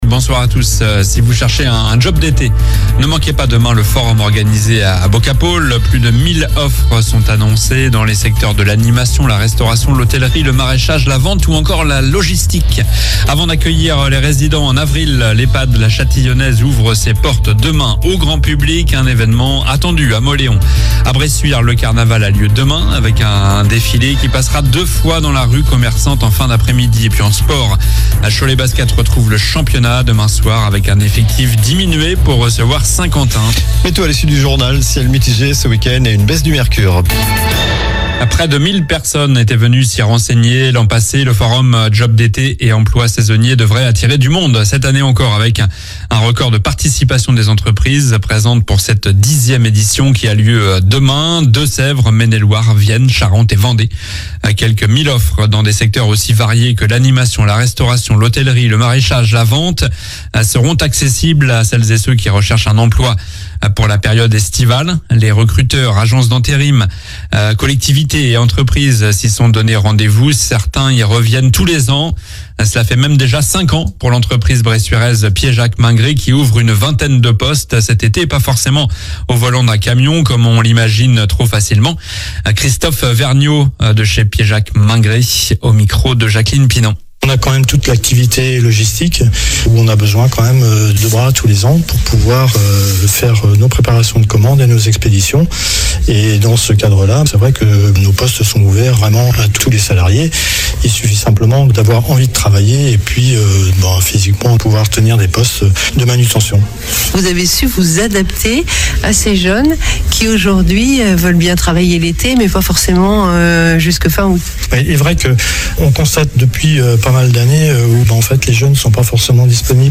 Journal du vendredi 22 mars (soir)